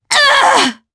Demia-Vox_Damage_jp_03.wav